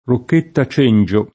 rokk%tta] top. — es.: Rocchetta Belbo [rokk%tta b$lbo] (Piem.), Rocchetta Palafea [rokk%tta palaf$a] (id.), Rocchetta Ligure [rokk%tta l&gure] (id.), Rocchetta Cengio [